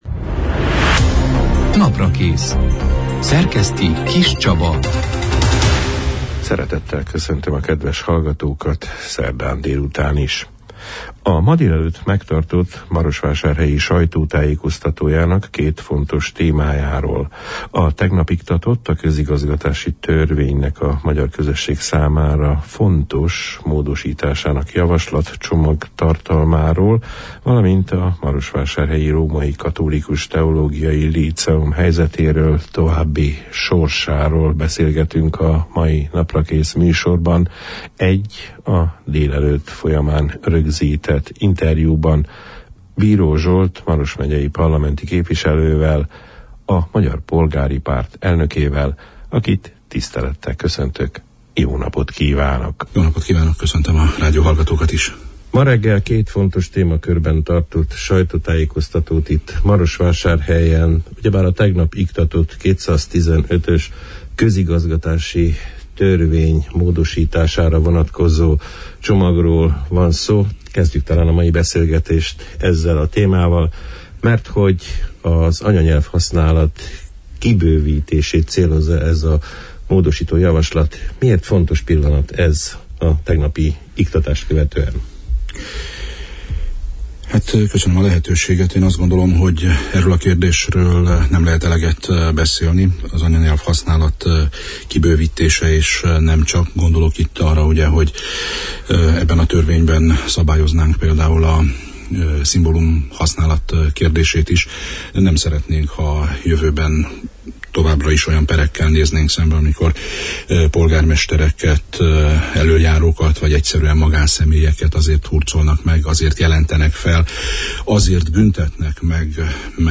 A ma délelőtt megtartott marosvásárhelyi sajtótájékoztatójának két jelentős témájáról, a tegnap iktatott, a közigazgatási törvénynek az anyanyelv-használat kiterjesztésére vonatkozó módosítása tartalmával kapcsolatos tudnivalókról, valamint a marosvásárhelyi római katolikus líceum jelenlegi helyzetéről és az oly sok kálváriát megért tanintézmény jövőjének rendezéséről beszélgettünk a május 24 – én, szerdán elhangzott Naprakész műsorban Bíró Zsolt parlamenti képviselővel, a Magyar Polgári Párt ( MPP ) elnökével.